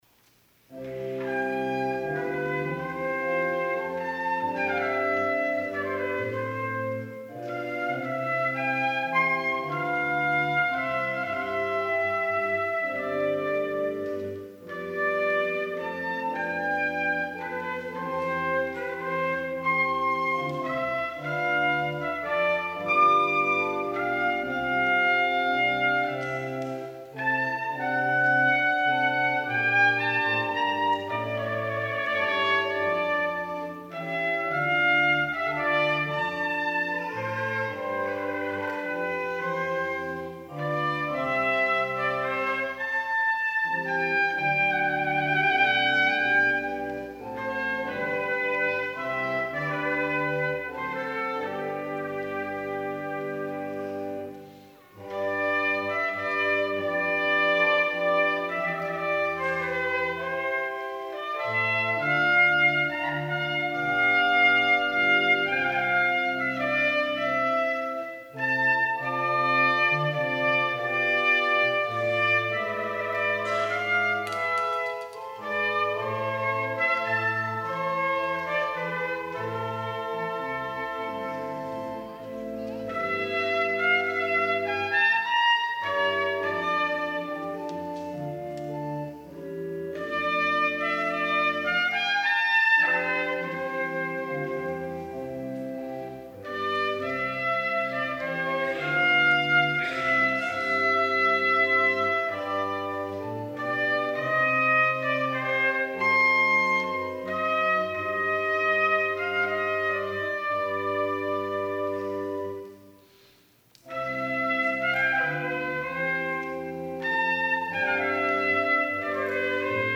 VOLUNTARY Siciliana
trumpet
organ